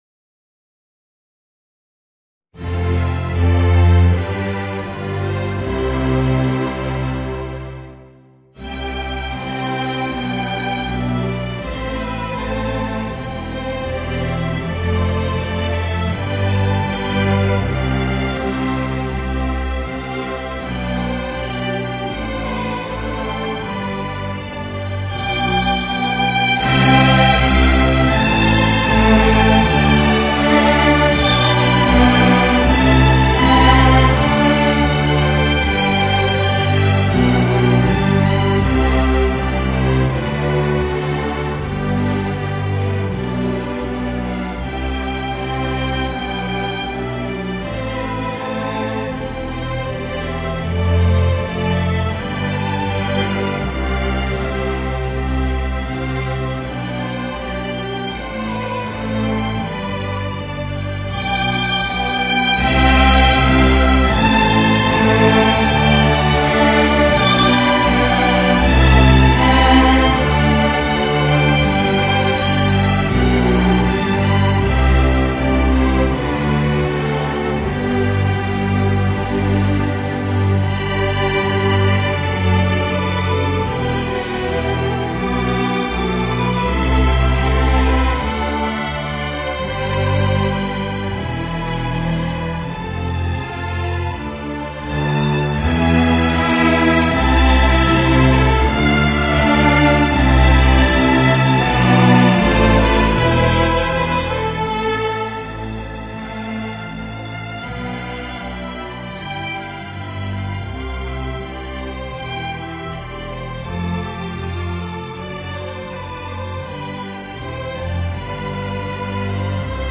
Voicing: Piccolo and String Quintet